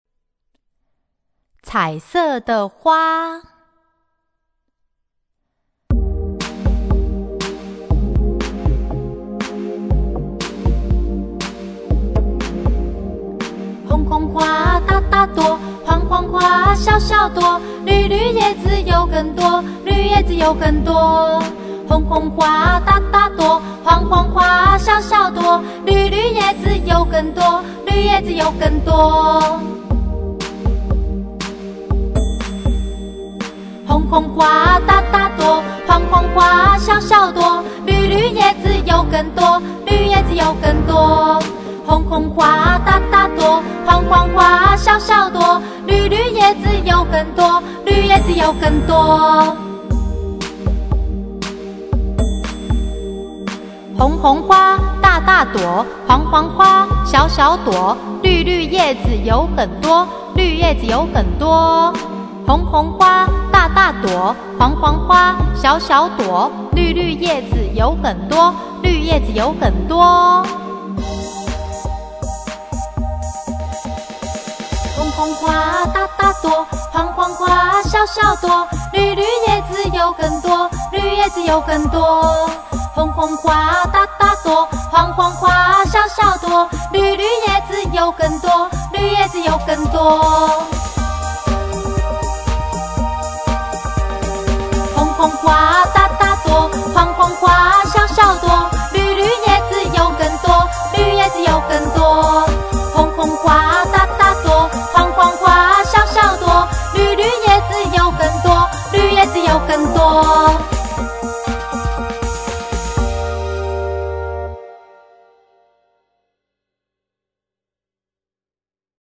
CD每首兒歌均譜寫輕快活潑的唱曲，增加幼兒學習情趣